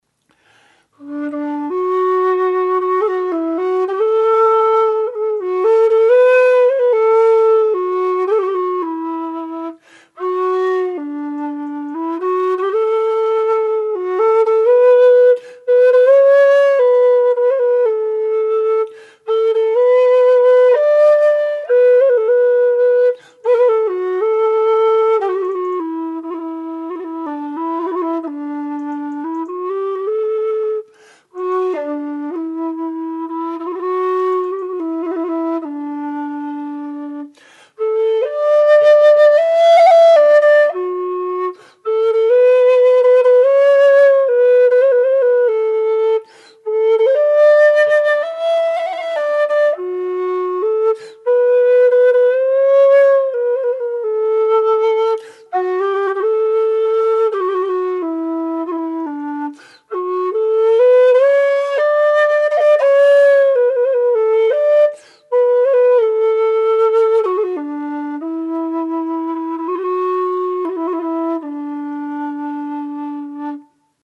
Low D whistle
made out of thin-walled aluminium tubing with 23mm bore